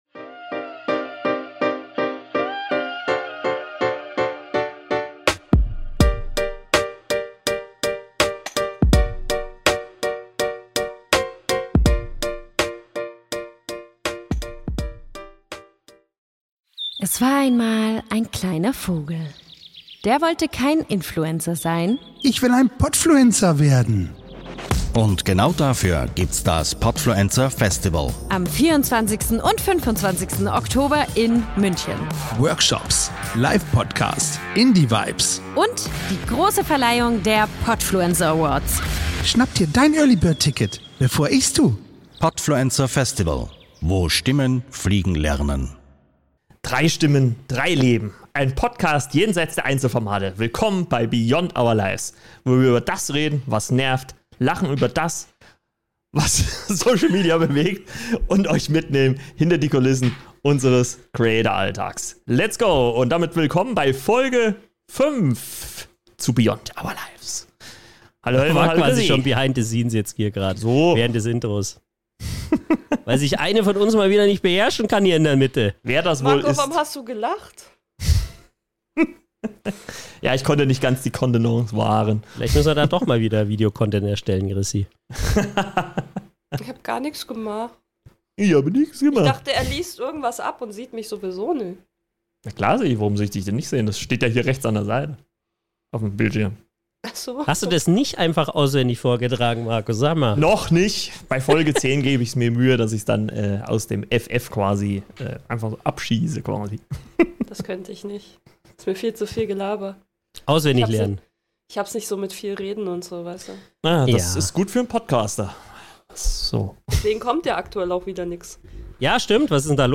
Drei Hosts im Gespräch